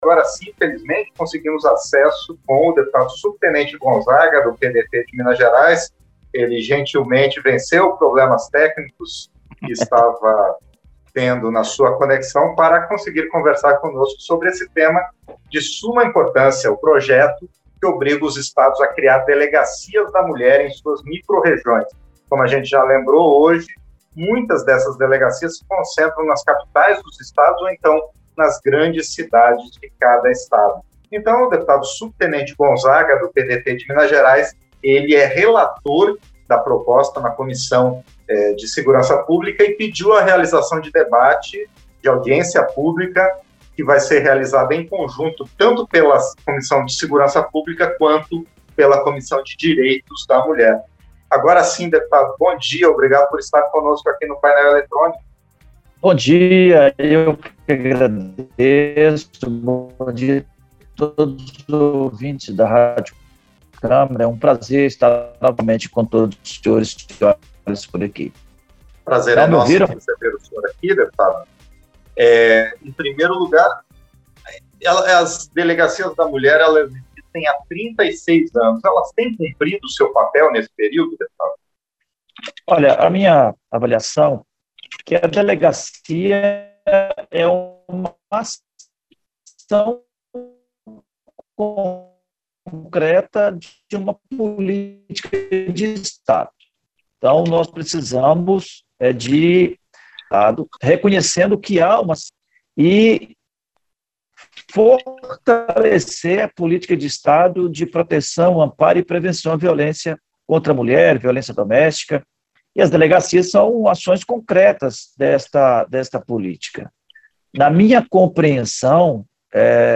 Entrevista - Dep. Subtenente Gonzaga (PDT-MG)